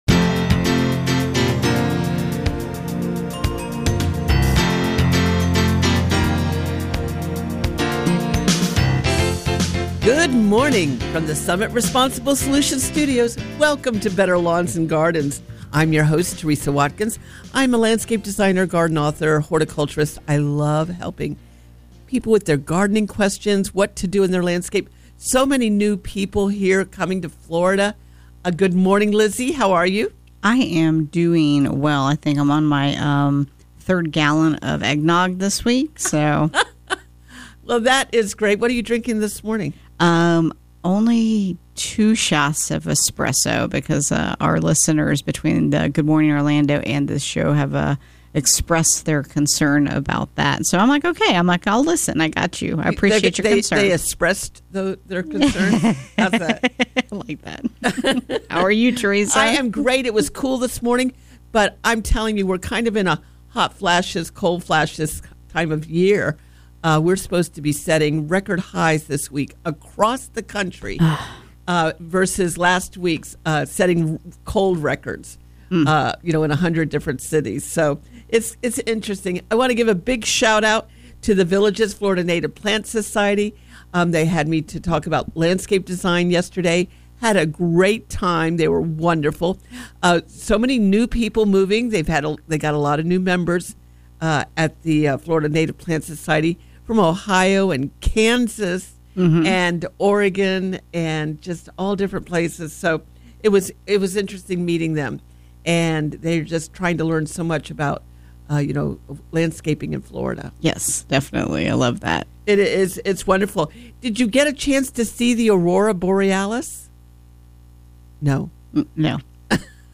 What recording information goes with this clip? Better Lawns and Gardens Hour 1 – Broadcasting live from the S ummit Responsible Solutions Studios.